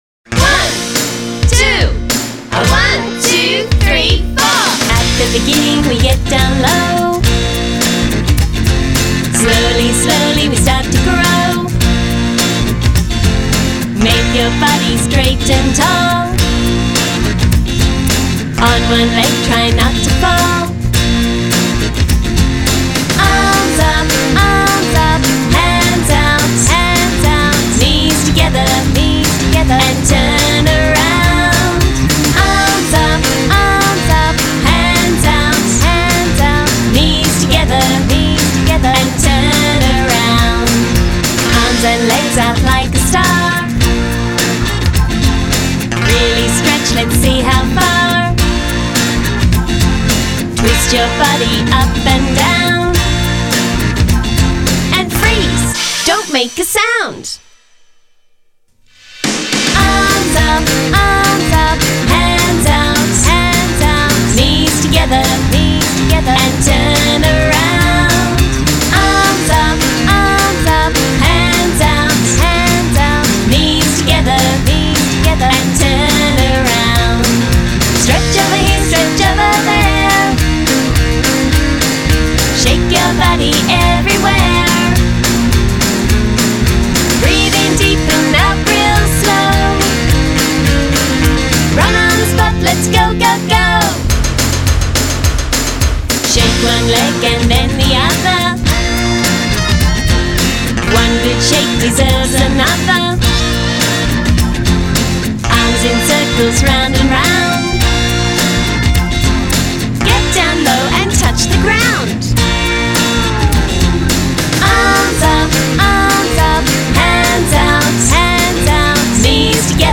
Genre: Children.